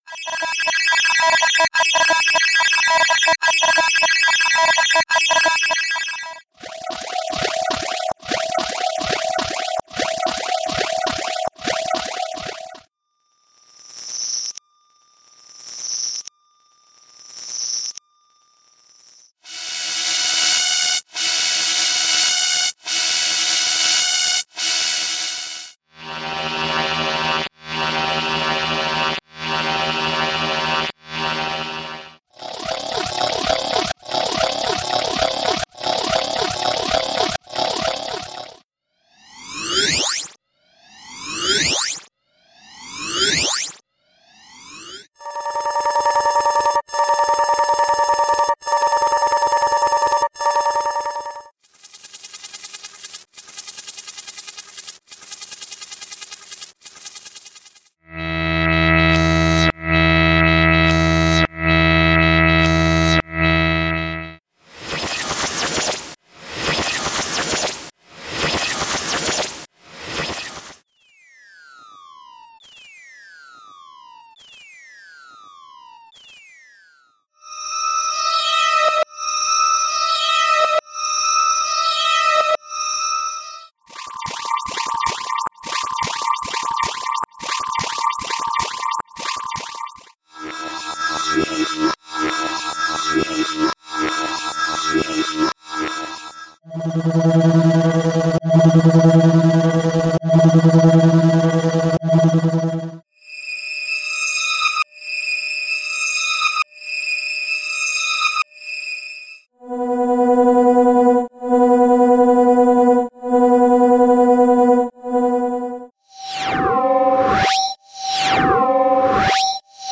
Sound Effects - SIGNALS - V8 - p1
Signals Actual Length: 1 Minute (60 Sec) Each Sound.